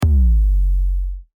Download Bass Drop sound effect for free.
Bass Drop